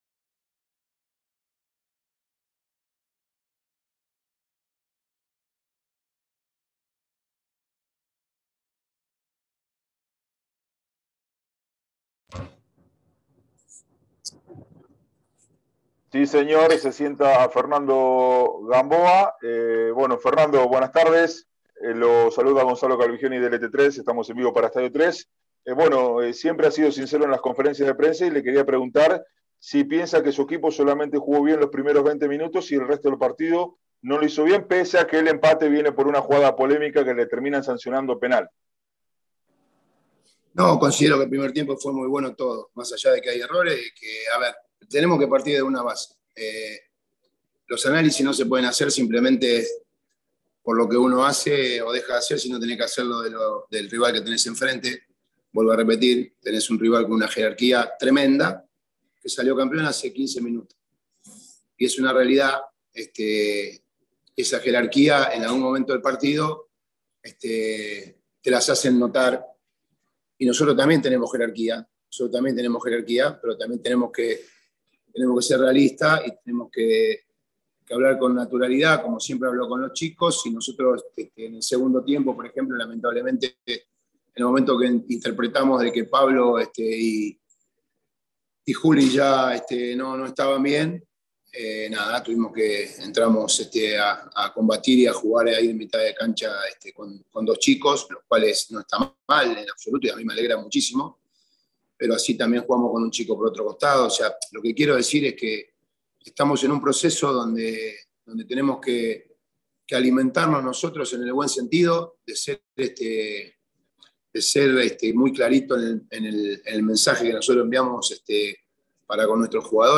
Tras el empate entre Newell’s y Colón por la fecha 10 de el torneo de la Liga Profesional de Fútbol, Fernando Gamboa, entrenador de la Lepra, realizó la habitual conferencia de prensa y se mostró muy enojado con los árbitros tras la polémica sanción de un penal a favor del local, lo que significó la igualdad del Sabalero.